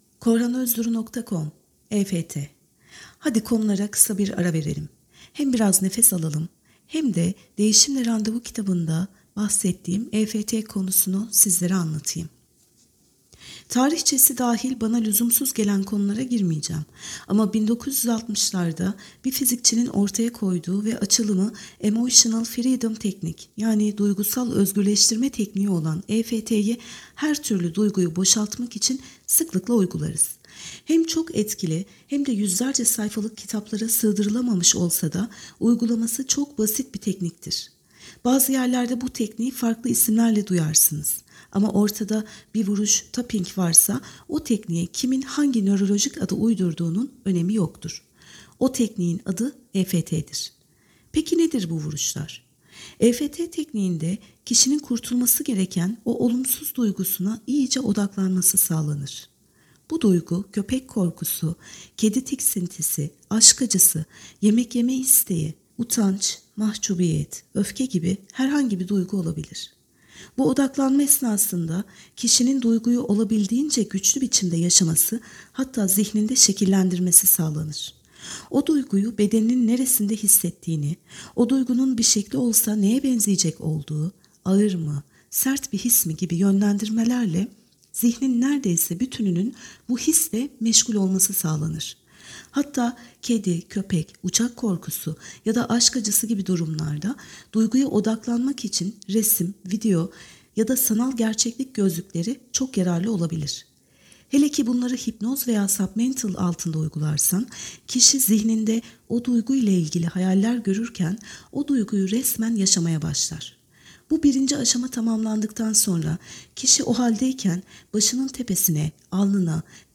EFTSesliOkuma.mp3